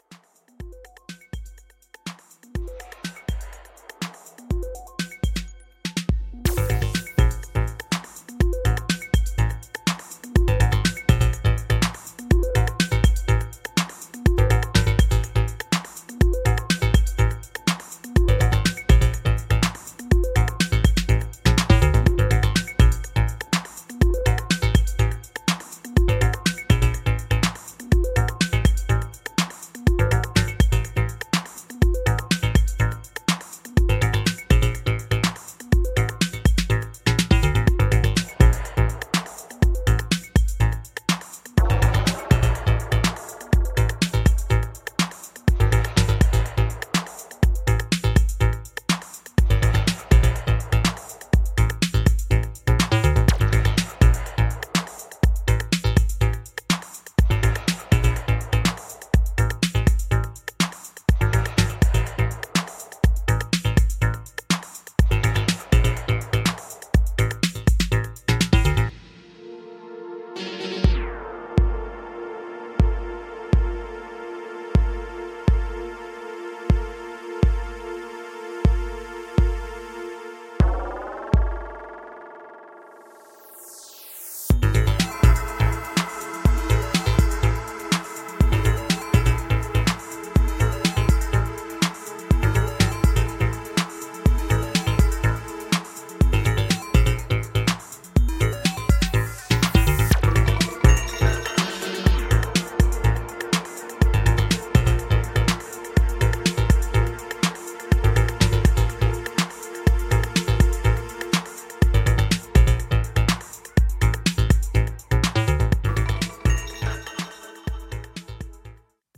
acid drenched electro-funk
Electro Techno